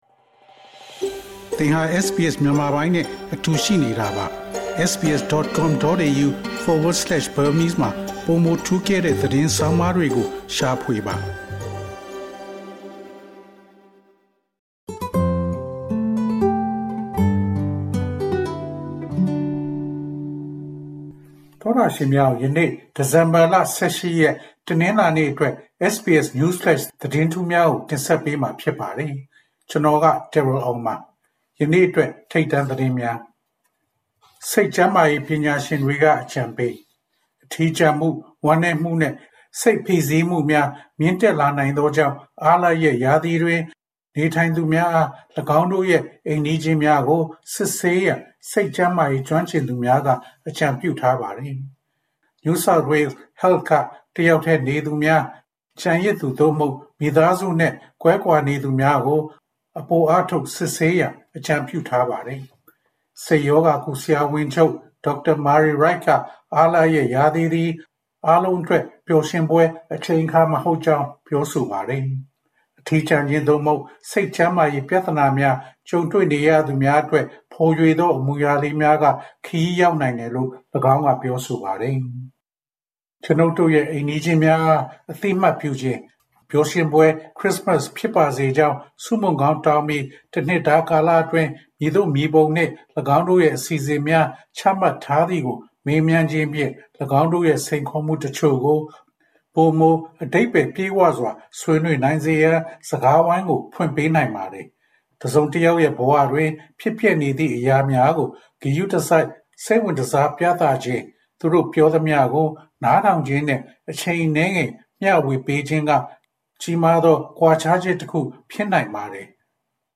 SBS Audio Burmese News Flash